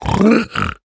Minecraft Version Minecraft Version snapshot Latest Release | Latest Snapshot snapshot / assets / minecraft / sounds / mob / piglin / celebrate3.ogg Compare With Compare With Latest Release | Latest Snapshot
celebrate3.ogg